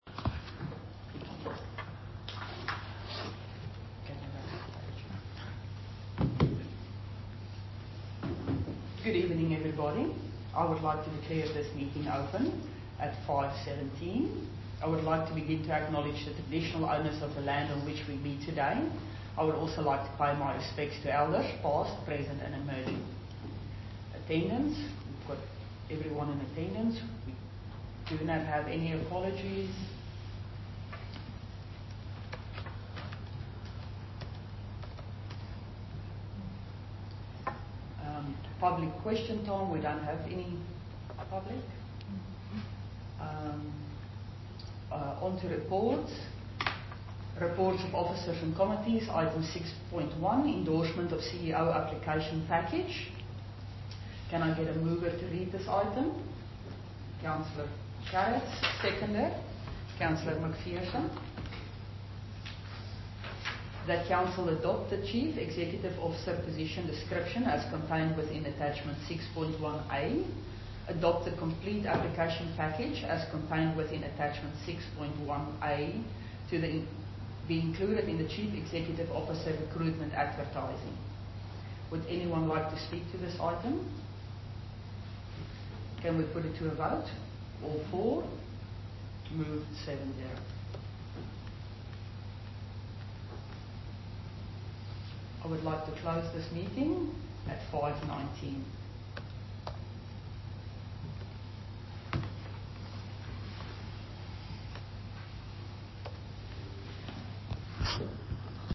Special Council Meeting - March 2026 » Shire of Boddington
recording-special-council-meeting-march-2026.mp3